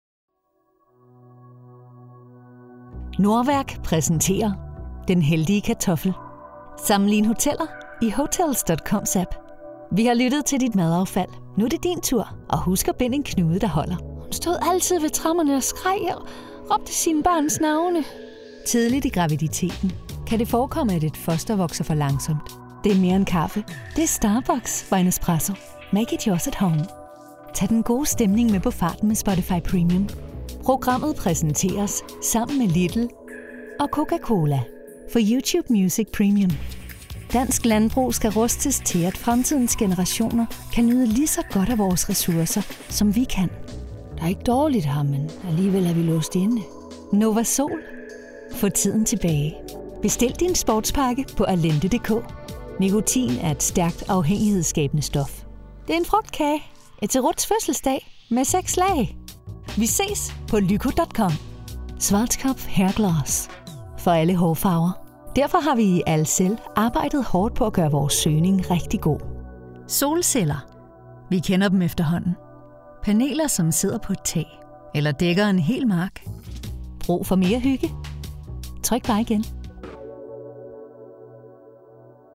Sprechprobe: Sonstiges (Muttersprache):
Voice Over Actress in Danish and English. Top professional Voice Over Talent and Actress in Danish, English and Scandinavian delivering the highest sound quality recorded in a professional studio.